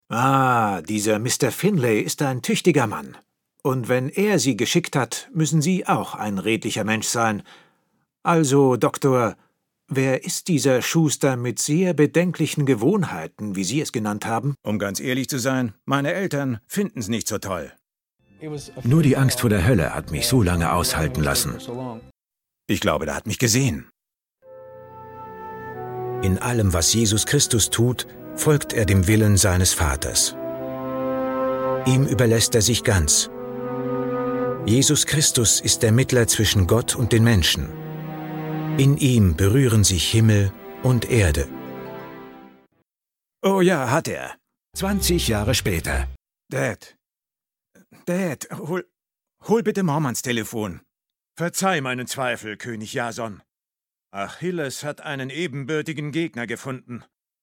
Profi Sprecher deutsch. Synchronsprecher, Werbesprecher.
Kein Dialekt
Sprechprobe: Werbung (Muttersprache):